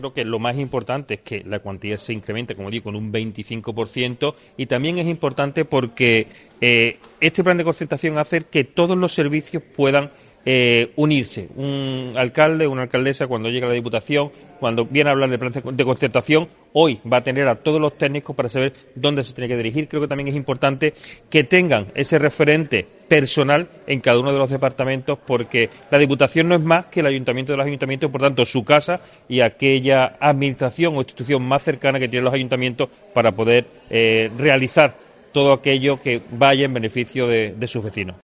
El presidente de Diputación, David Toscano, explica a los ayuntamientos de la provincia las novedades del Plan de Concertación dotado con 21 millones de euros
David Toscano, presidente de la Diputación de Huelva